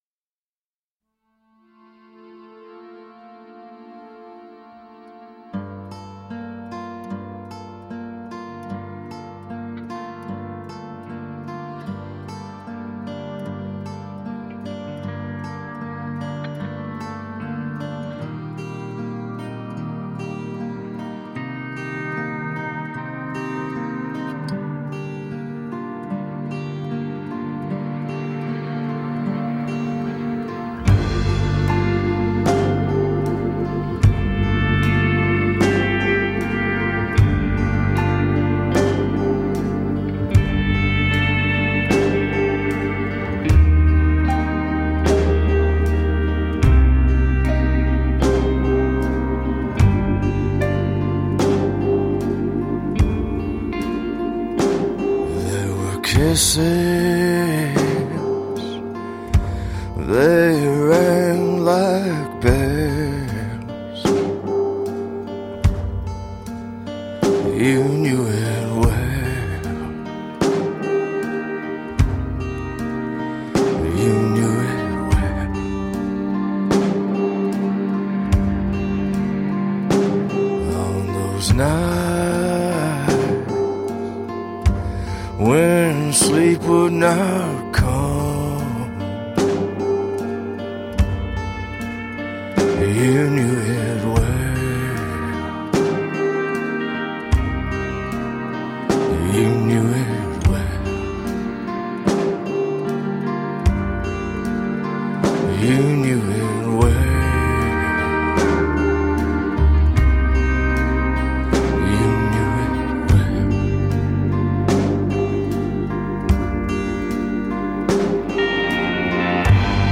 Darkly gorgeous, cinematic folk.